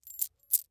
Coin Jingle in Hand Short Sound
household